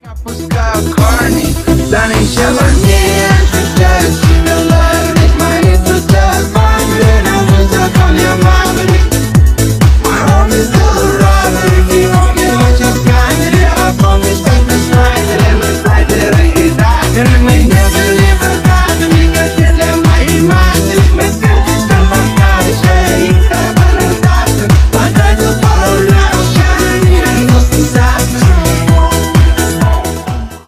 Ремикс # Рэп и Хип Хоп
клубные